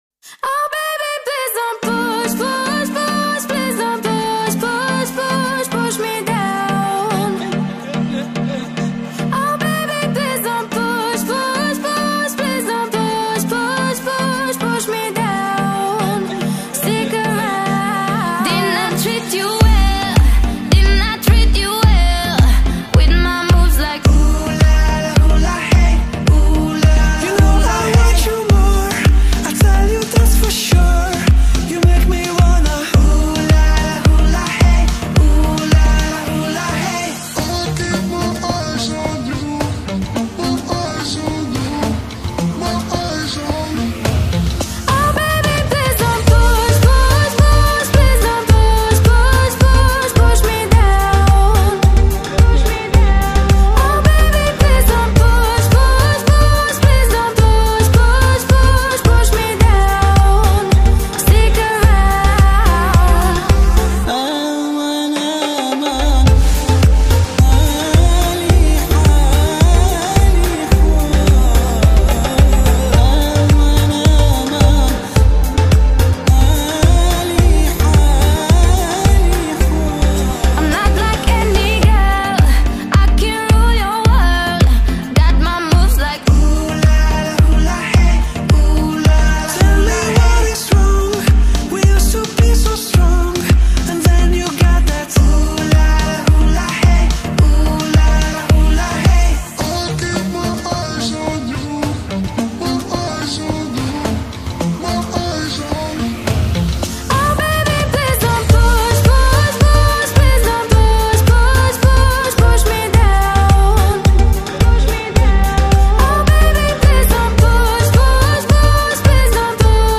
Главная » Файлы » Поп Музыка Категория